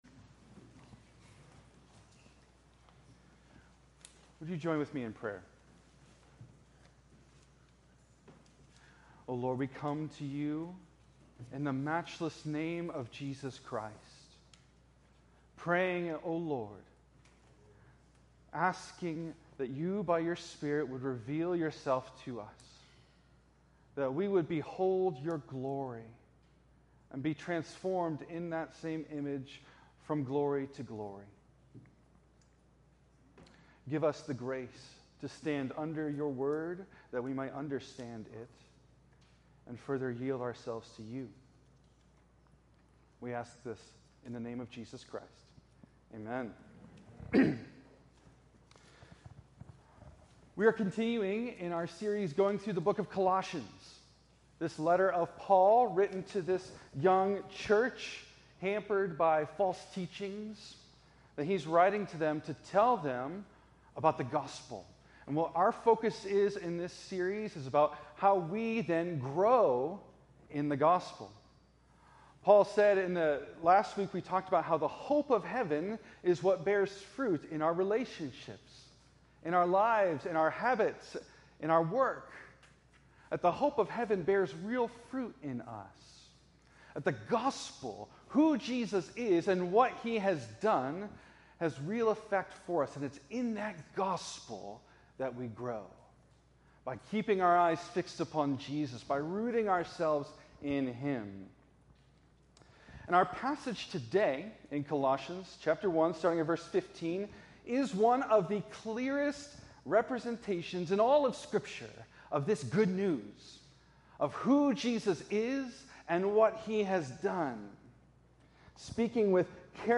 preaches on Colossians 1:15-23